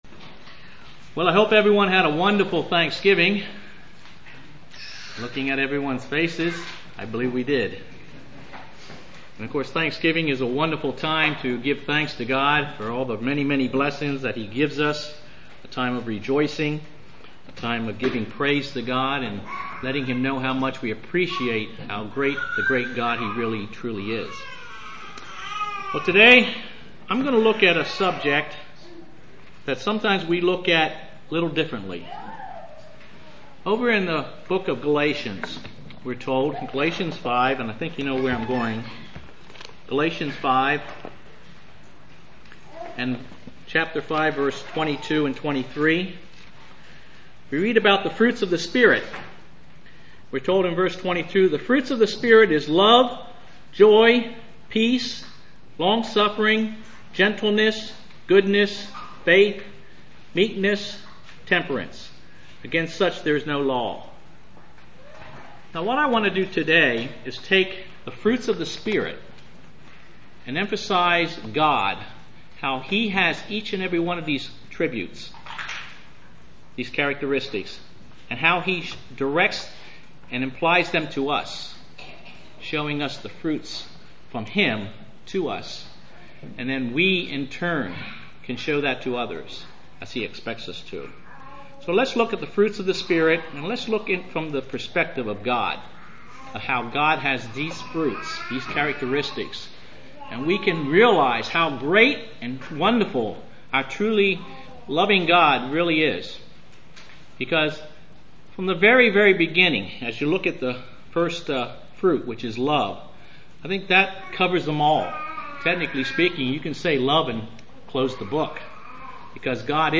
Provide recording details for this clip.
Given in Lewistown, PA